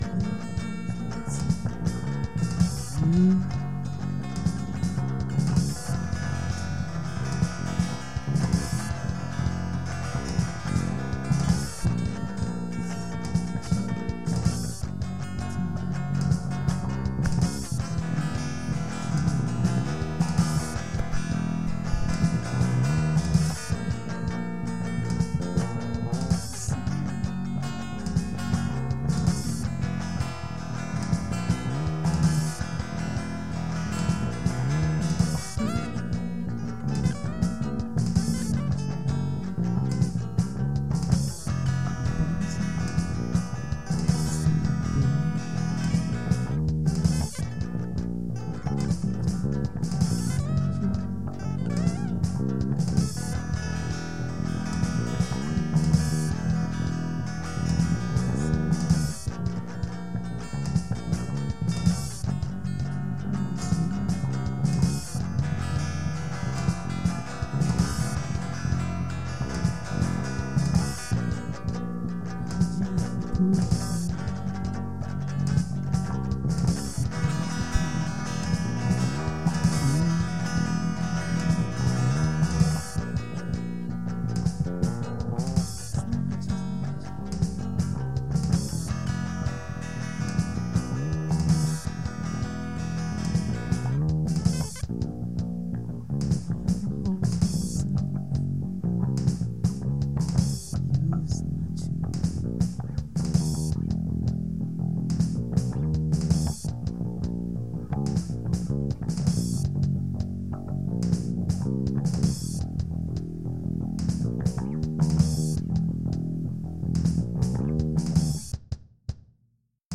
drums music supercollider abg